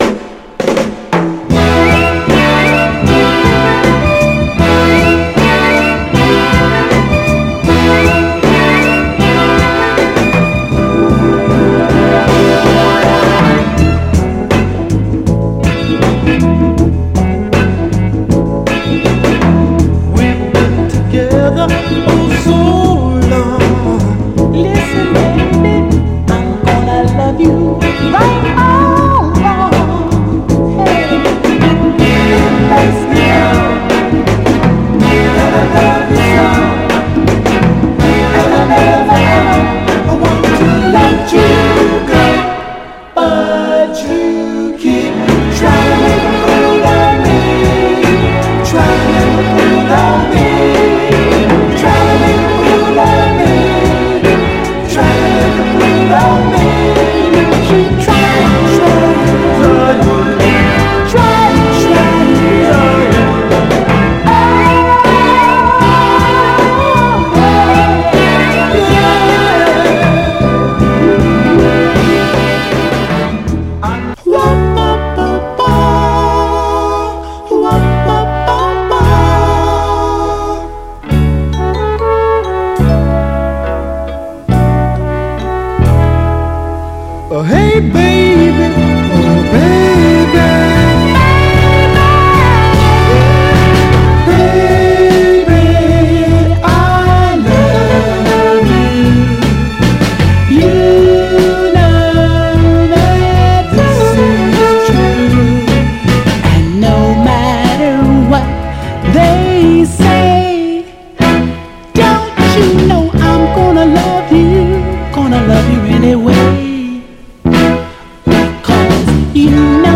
盤はエッジ中心にごく細かいスレありますが、グロスがありプレイ概ね良好です。
FORMAT 7"
※試聴音源は実際にお送りする商品から録音したものです※